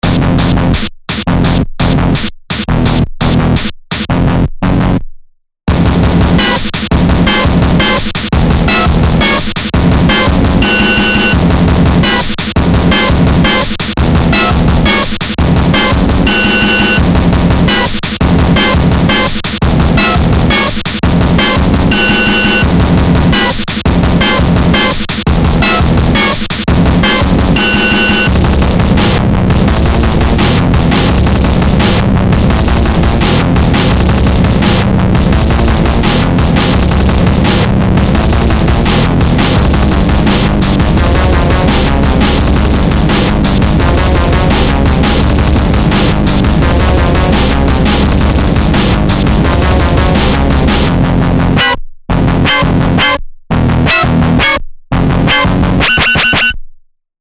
breakcore, shitcore, noisecore, glitch,